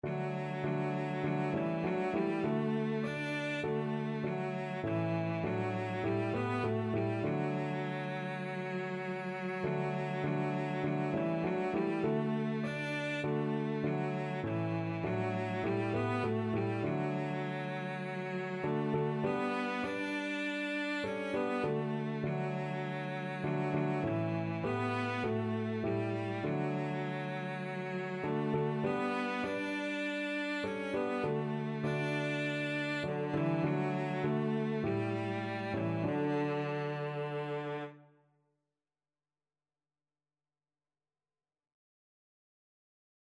4/4 (View more 4/4 Music)
Classical (View more Classical Cello Music)